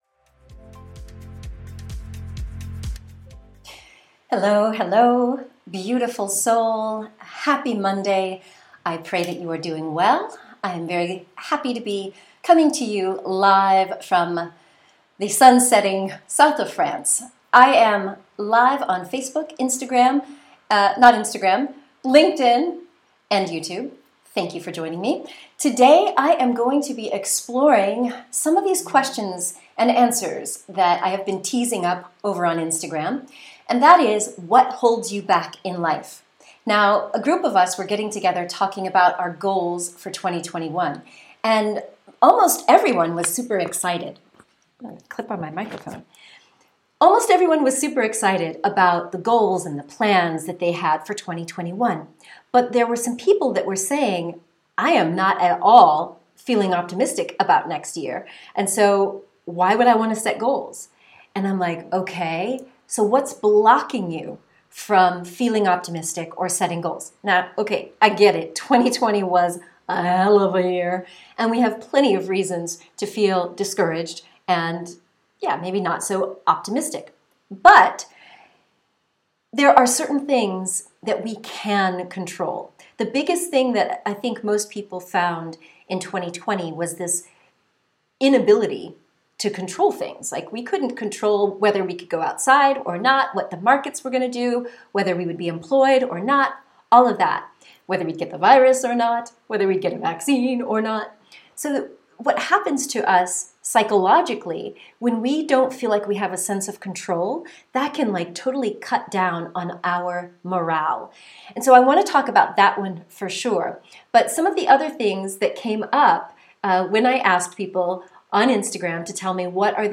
What holds you back in life? (Part 1) Q&A Replay - 2020 | Resilience + Self-Love + Holistic Healing of Trauma & Adverse Childhood Experiences
I wondered what other people were struggling with and then I hosted a live session on YouTube , Facebook , and LinkedIn , check out the replay below.